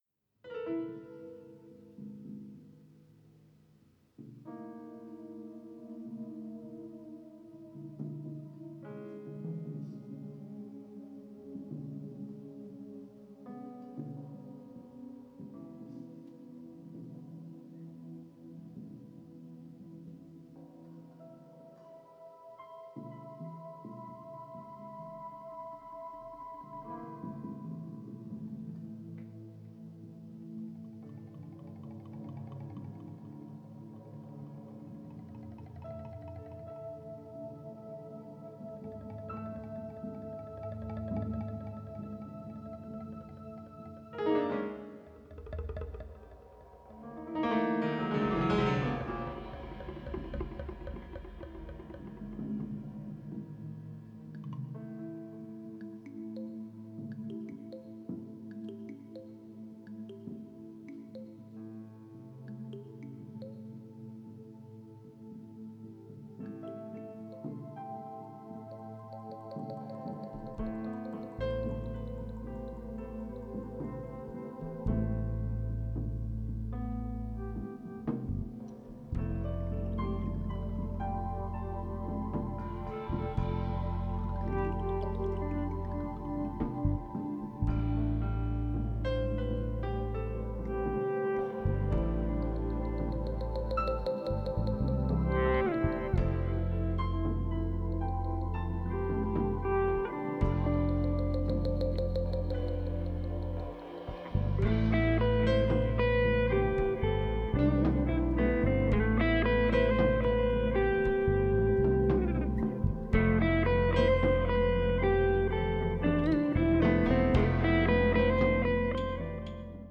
Experimental instrumental music
sax, keyboards
guitar